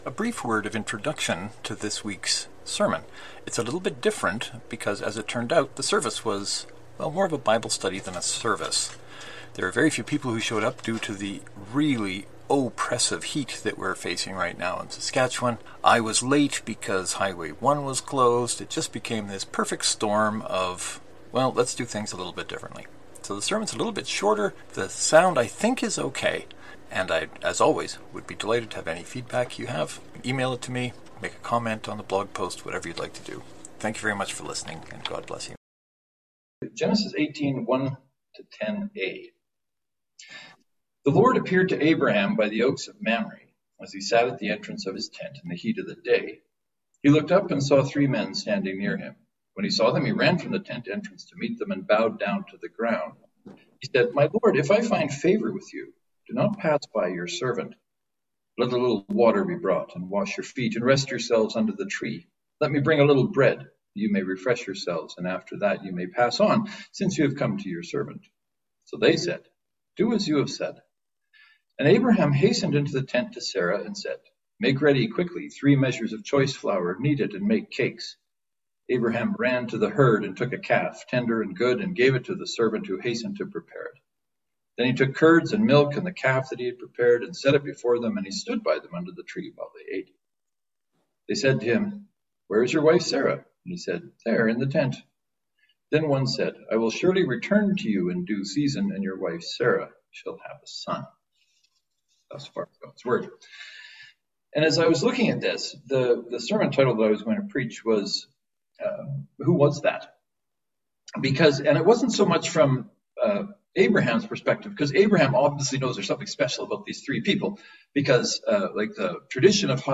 The sermon begins with an introduction, recorded in my basement, explaining why this sermon is a bit different.